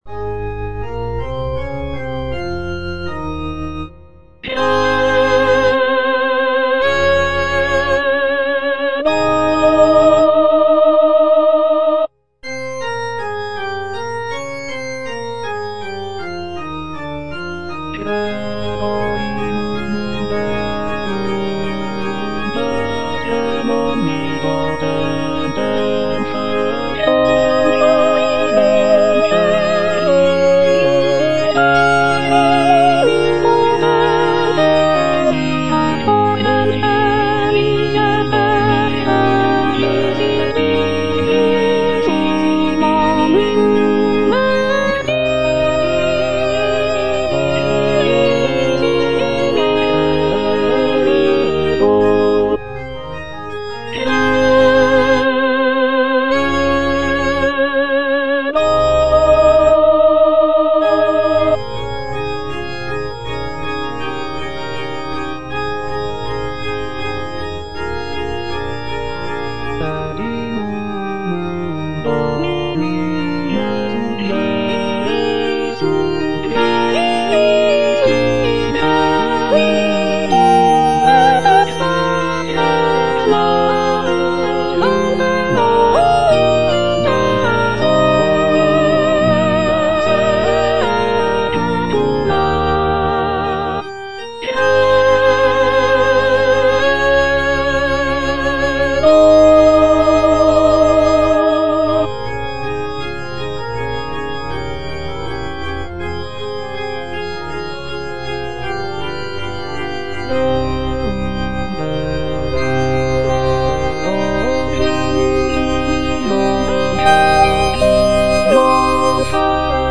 Soprano (Emphasised voice and other voices) Ads stop
sacred choral work